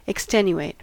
Ääntäminen
Ääntäminen US : IPA : [ɪkˈstɛn.jʊˌeɪt] Tuntematon aksentti: IPA : /ɪkˈstɛnjueɪt/ Haettu sana löytyi näillä lähdekielillä: englanti Käännöksiä ei löytynyt valitulle kohdekielelle.